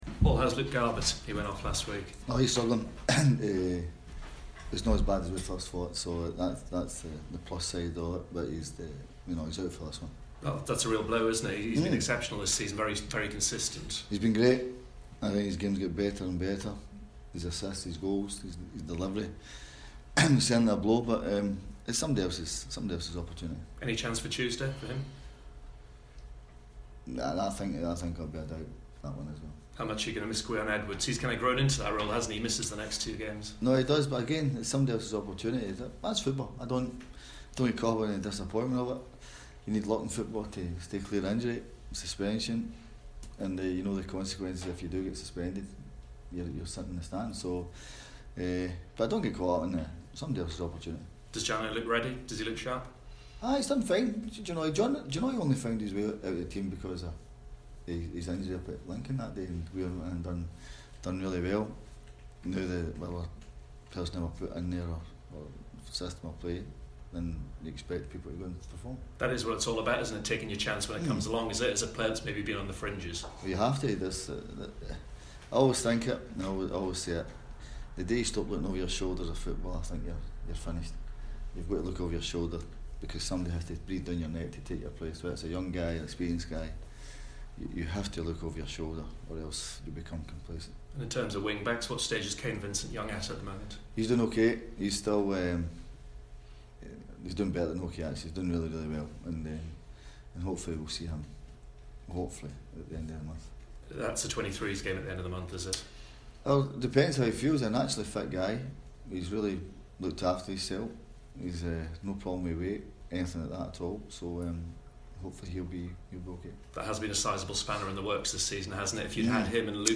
PRESS CONFERENCE: Lambert looks ahead to Town's visit to Sunderland